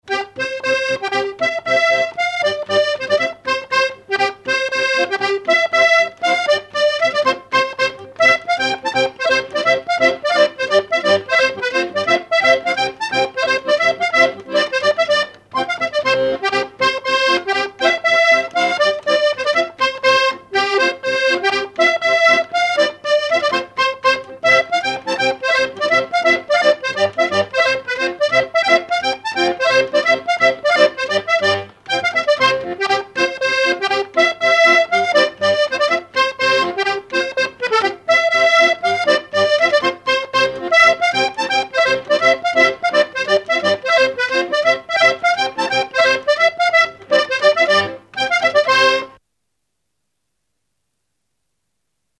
Enregistrement original de l'édition sur disque vinyle
musicien sarthois, musique pour les assauts de danse et le bal.
accordéon(s), accordéoniste ; musique traditionnelle ;
danse : mazurka-polka ;
Pièce musicale inédite